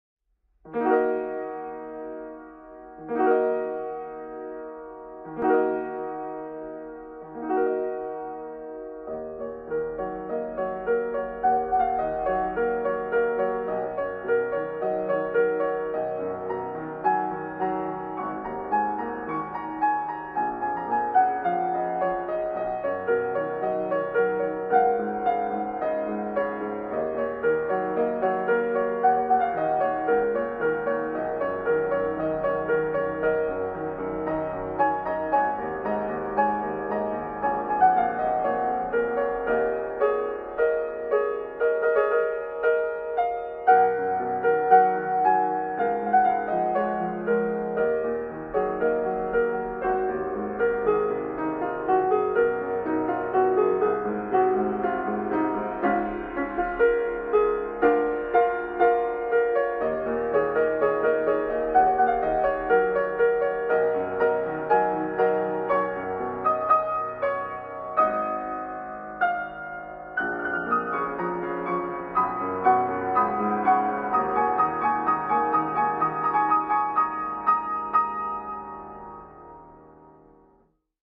- 生ピアノ（生演奏） シリアス
エンディング , シリアス